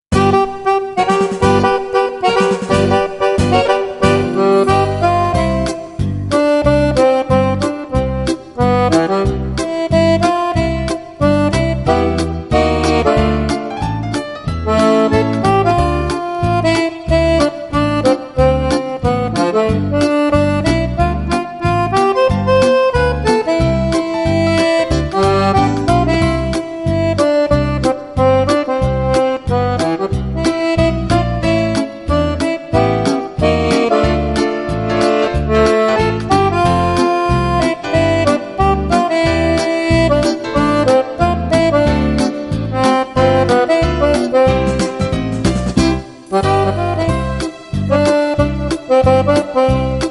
15 ballabili per Fisarmonica